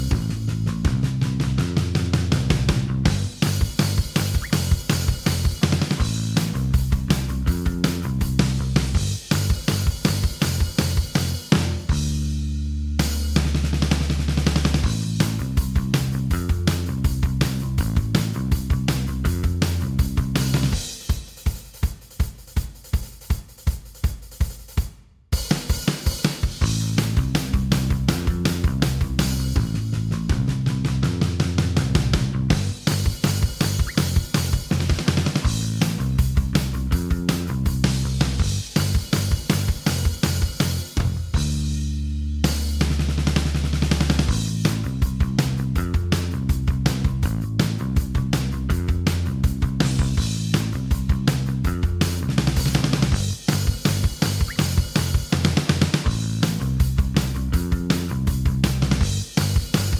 Minus Main Guitar For Guitarists 2:15 Buy £1.50